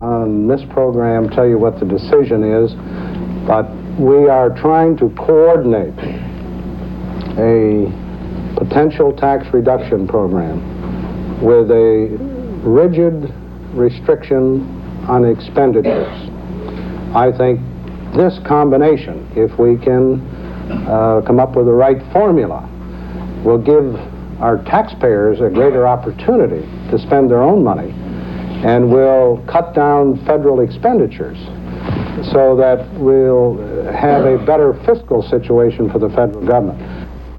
Gerald Ford says that he seeks a formula to tie the extended tax relief to a program for keeping government expenditures down
Broadcast on CBS-TV, October 2, 1975.